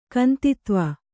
♪ kantitva